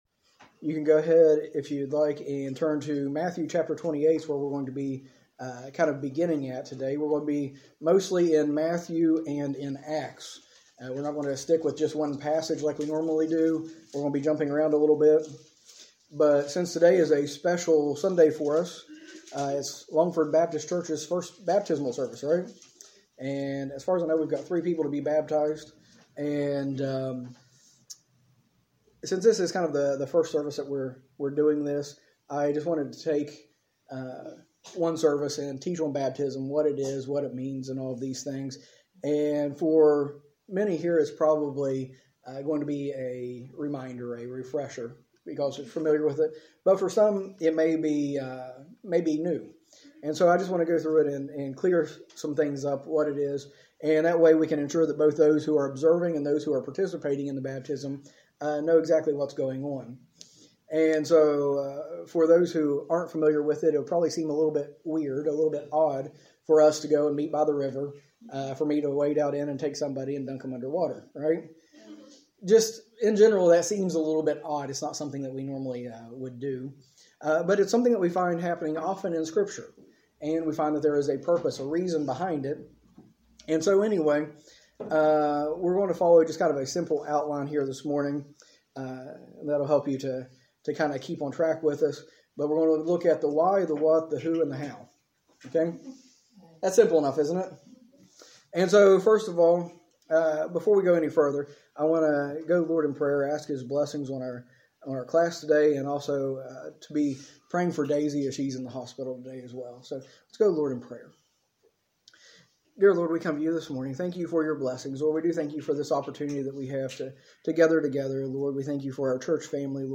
A message from the series "Series Breaks."